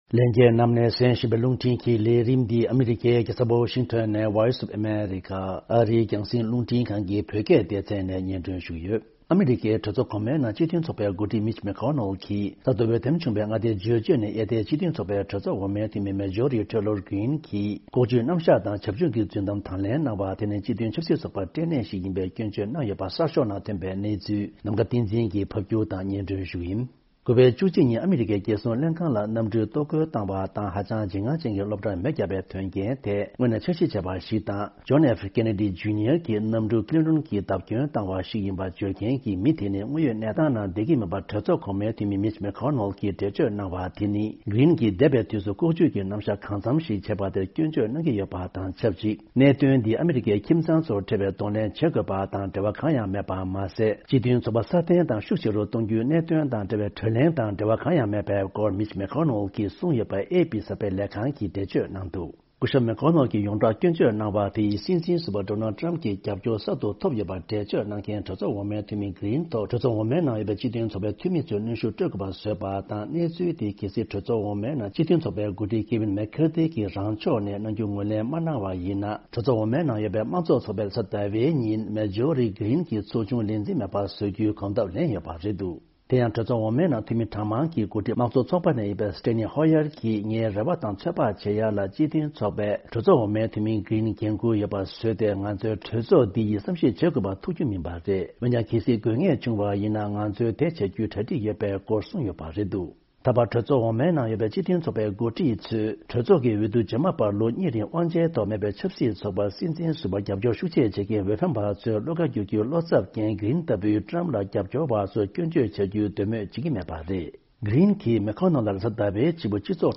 ཕབ་སྒྱུར་དང་སྙན་སྒྲོན་ཞུ་རྒྱུ་རེད།།